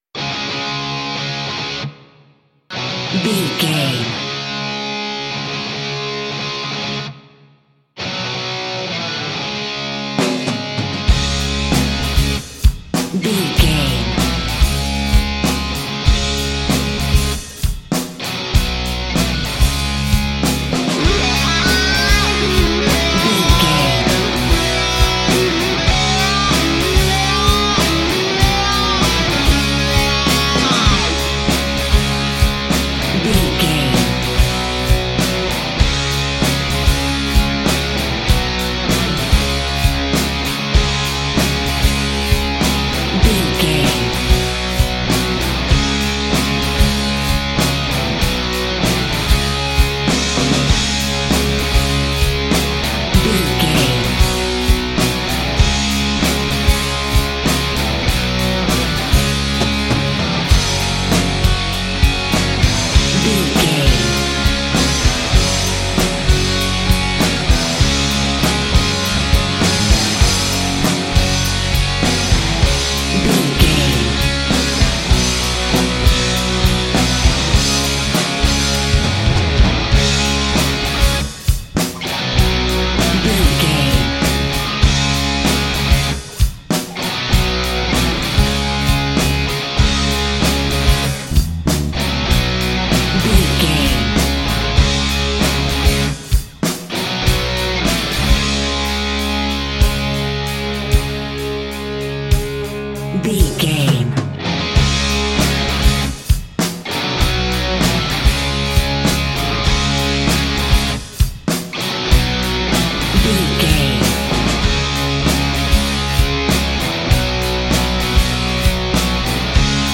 Ionian/Major
C#
electric guitar
bass guitar
drums
hard rock
aggressive
energetic
intense
nu metal
alternative metal